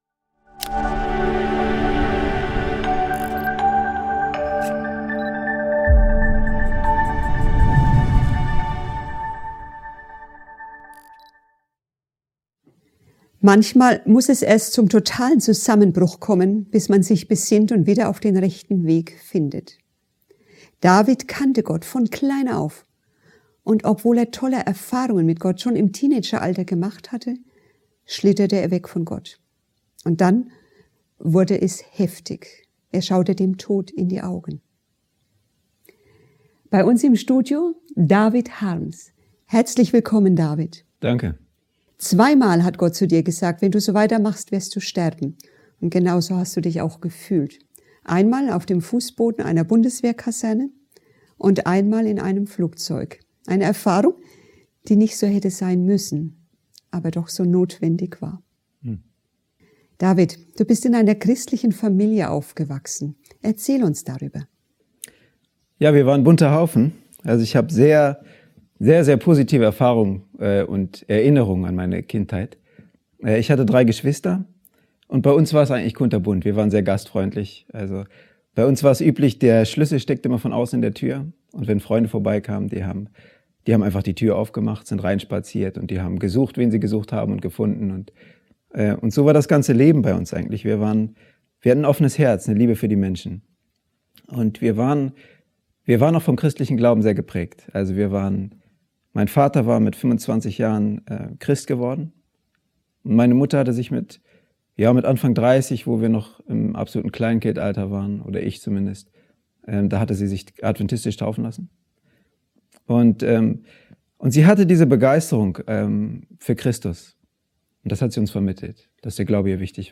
Seine bewegende Geschichte führt durch Abenteuer, verlorene Heimat und den unzerstörbaren Glauben, der schließlich zur Heilung und Neuanfang führt. Ein mitreißender Vortrag über Fall und Auferstehung, Gnade und Hoffnung.